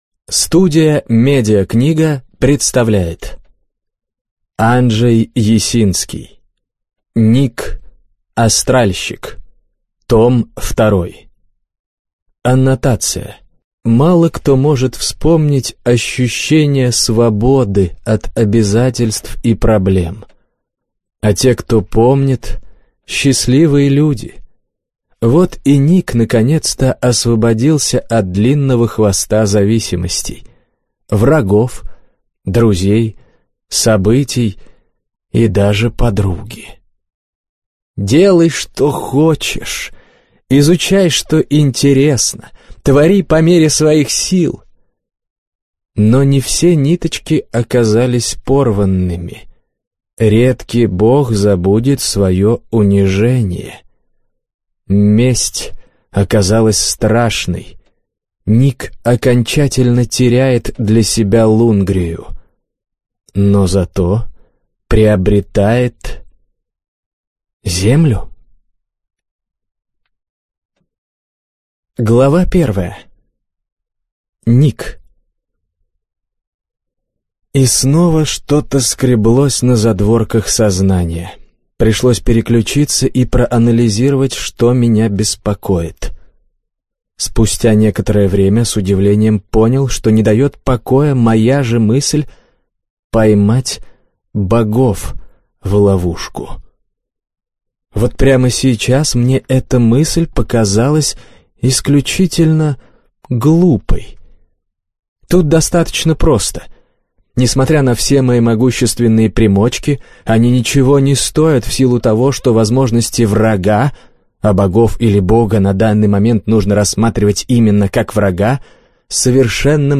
Аудиокнига Ник. Астральщик. Том 2 | Библиотека аудиокниг